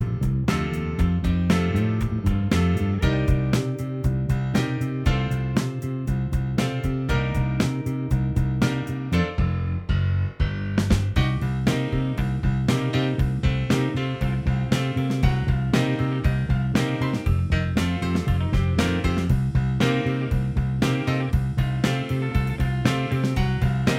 Minus Lead Guitar Pop (1960s) 3:00 Buy £1.50